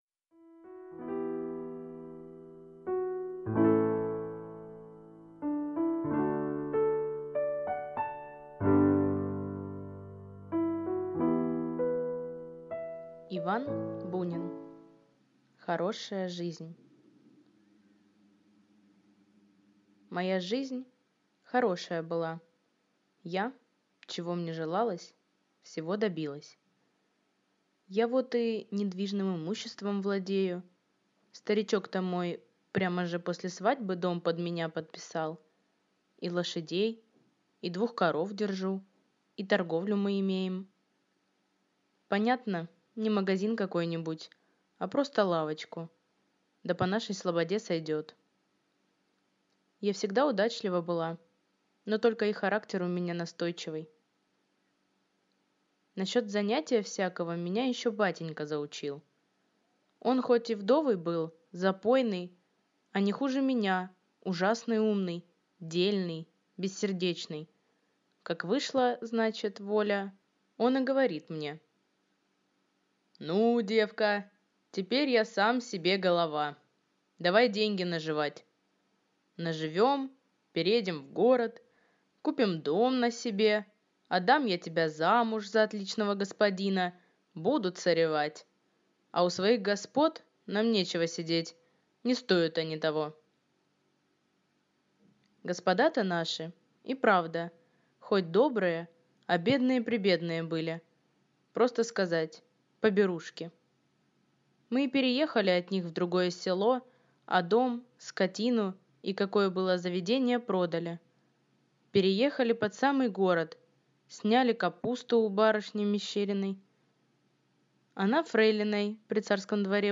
Аудиокнига Хорошая жизнь | Библиотека аудиокниг